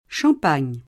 š3p#n’] top. f. (Fr.) — italianizz., oggi di rado, in Sciampagna [+šamp#n’n’a] (e cfr. campagna) — perlopiù con c‑ minusc. (e con s- minusc.) come nome del vino (s. m.; ma nella forma sciampagna, già domin. nel ’700 e ’800, anche s. f.)